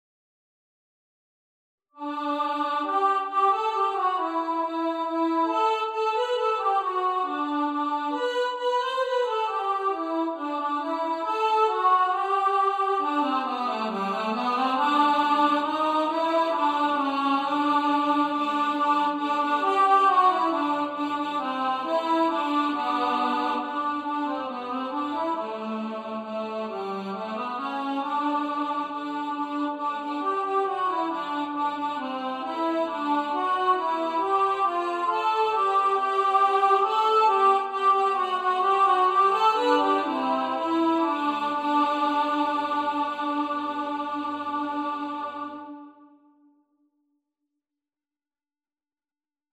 We-Wish-You-A-Merry-Christmas-Alto.mp3